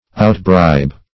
Outbribe \Out*bribe"\, v. t. To surpass in bribing.